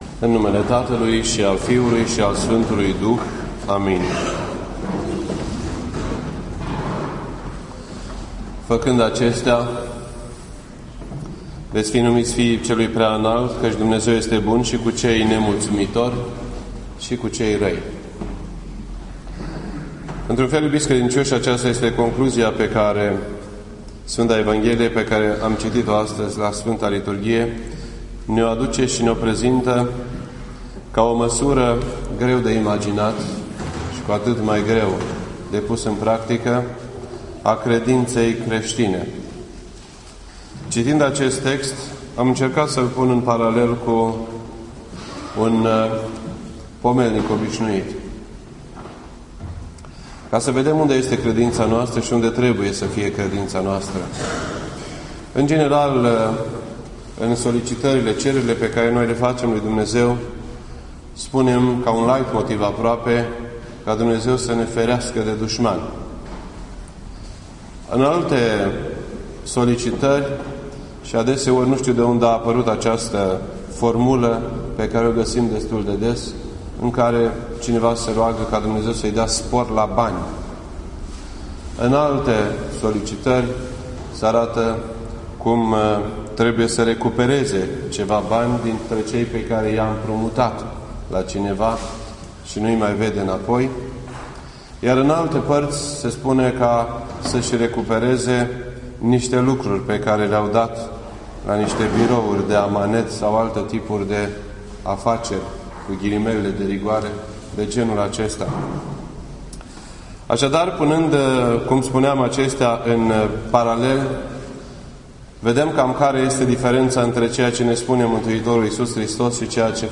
This entry was posted on Sunday, September 30th, 2012 at 8:14 PM and is filed under Predici ortodoxe in format audio.